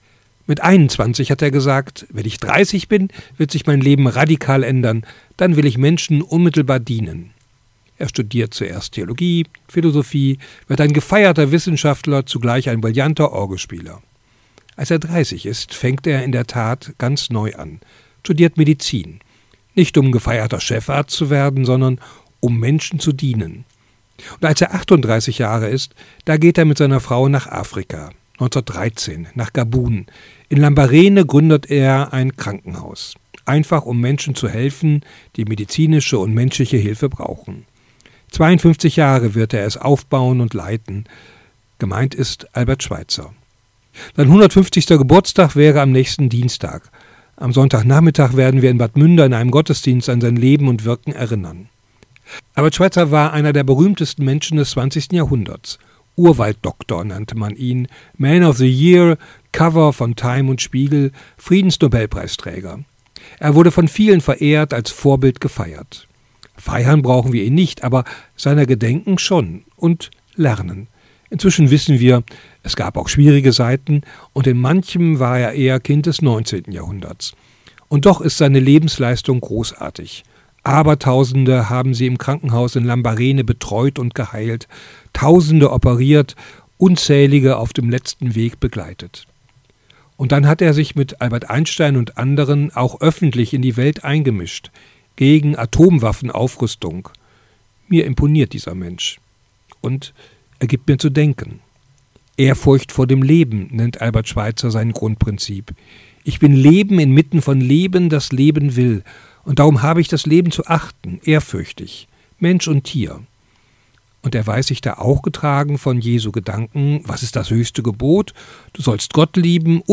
Radioandacht vom 9. Januar